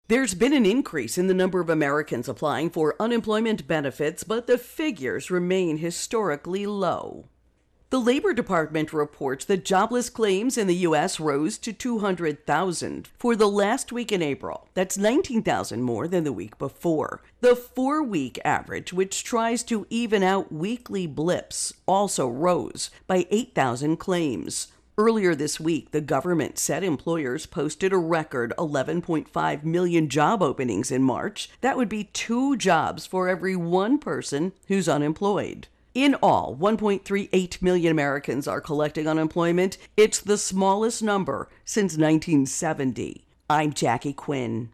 Unemployment Benefits Intro and Voicer